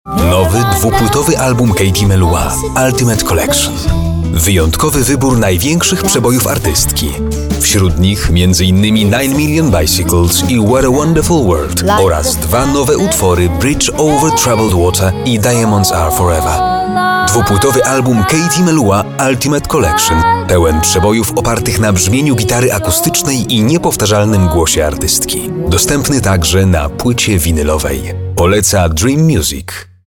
Male 30-50 lat
Narracja
Spot reklamowy